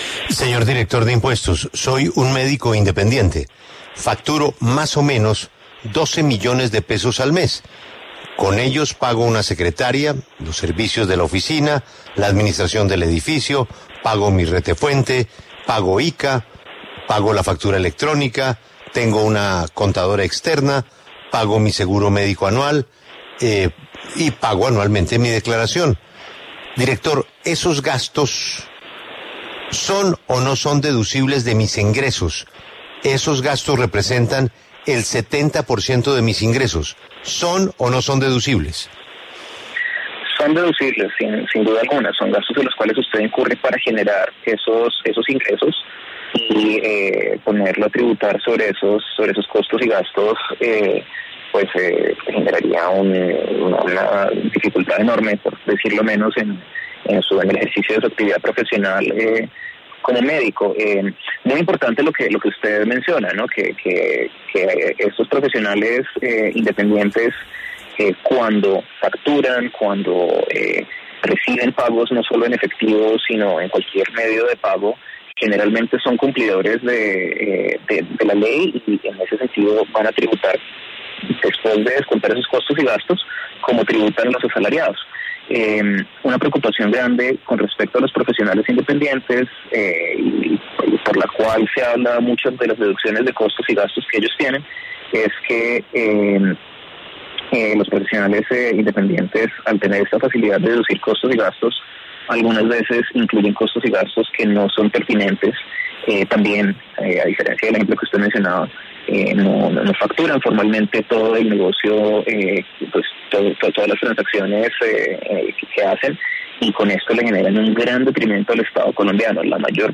Luis Carlos Reyes, el director de la DIAN, habló en La W sobre aquellos costos y gastos que serían deducibles y cómo se controlará la evasión.